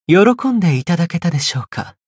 觉醒语音 您是否满意？